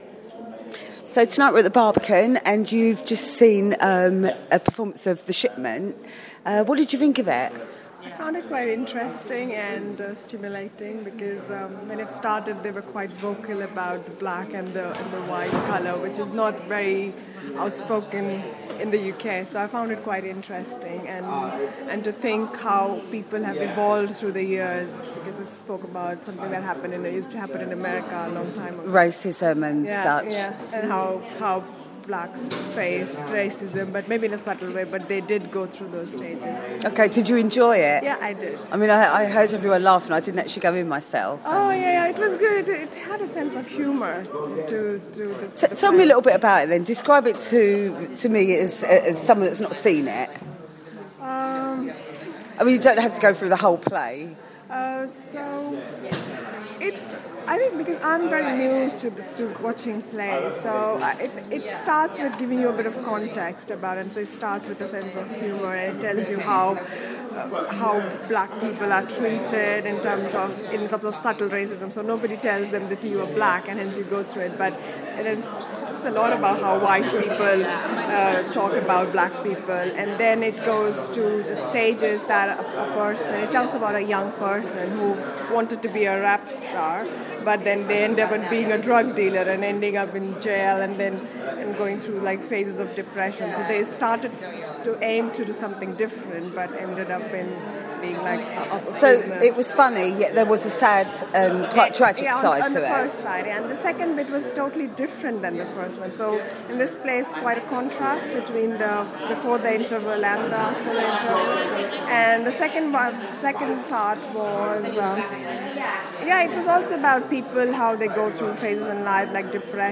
The Shipment, a play by Young Jean Lee performed at the Barbican Centre. A member of the audience gives me her reaction.